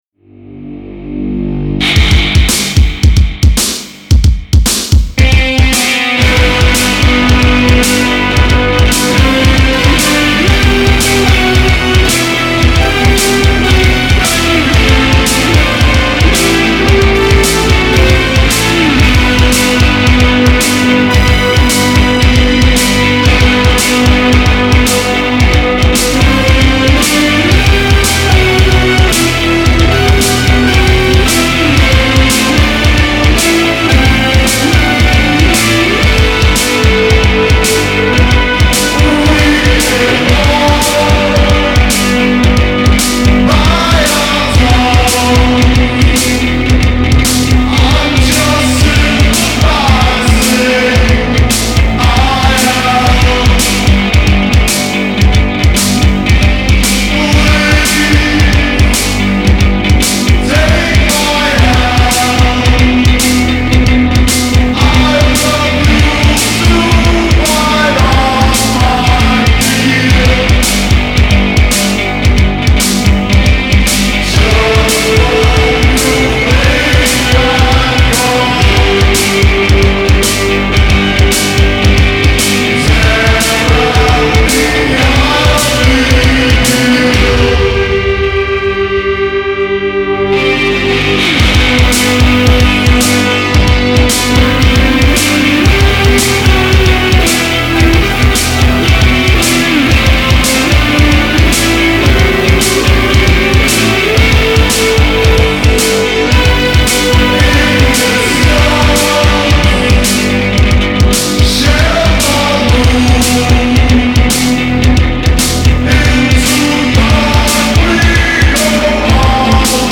densely layered, decibel pushing, murky dissonance